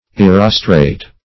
Erostrate \E*ros"trate\, a.